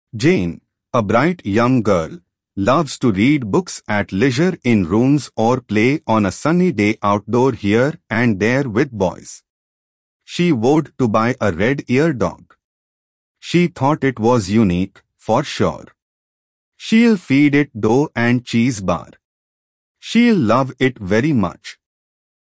Pangram_en-IN-PrabhatNeural_Jane, a bright.mp3.mp3